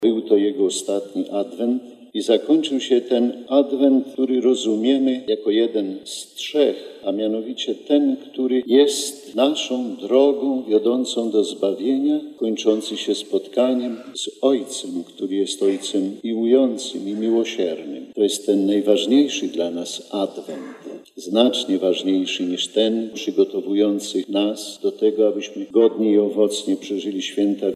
Dziś jest on już z Chrystusem – mówił w homilii biskup warszawsko-praski.